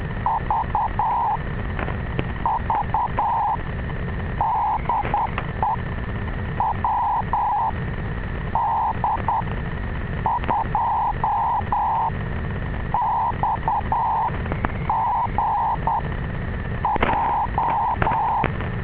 So I removed the coupling coil to the signal generator, and ran the receiver as a simple crystal set.